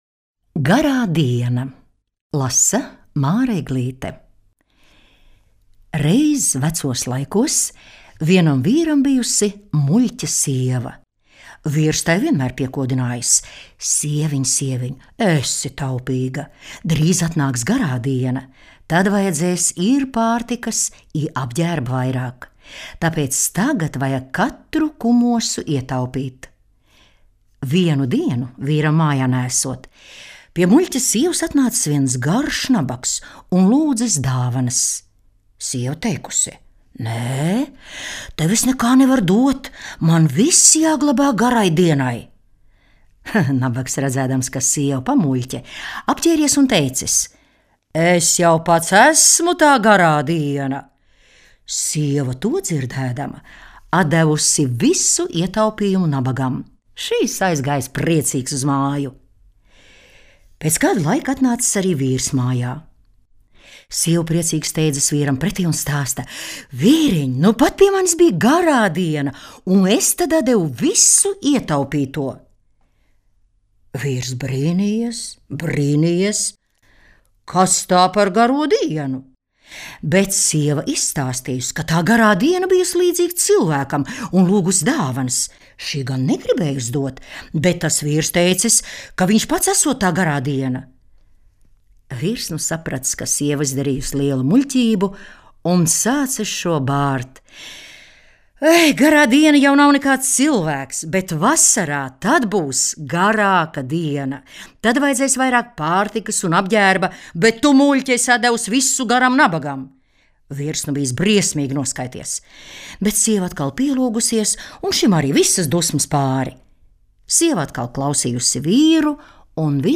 Teicējs